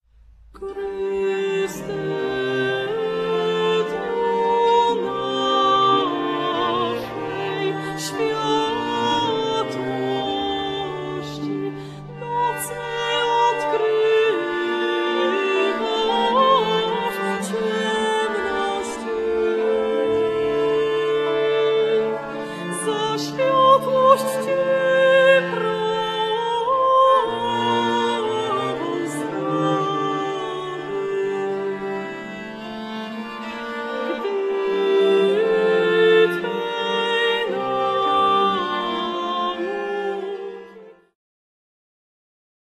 sopran
fidel
harfa, bęben, śpiew
puzon
kontratenor
baryton